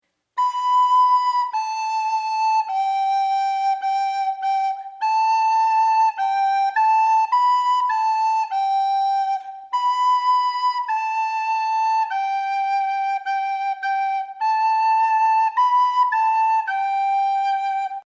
Voicing: Soprano Re